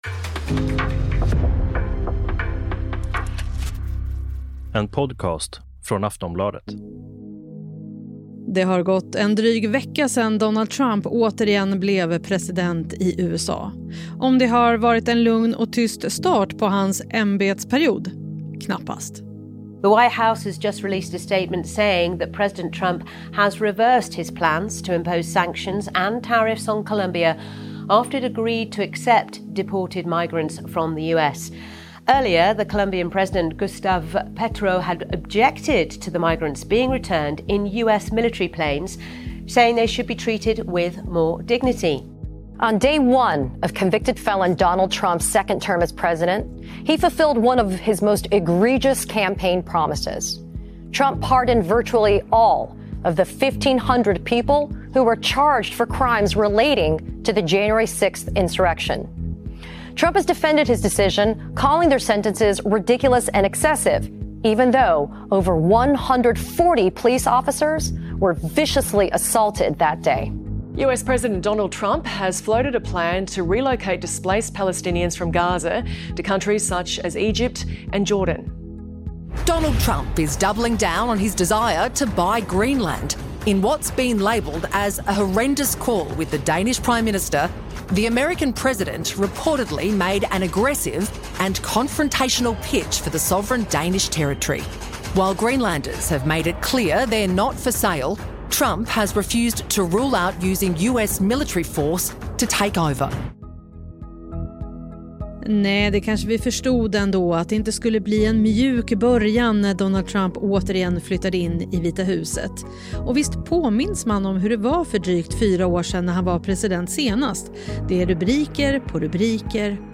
Klipp från: BBC News, ABC News, The project, MSNBC.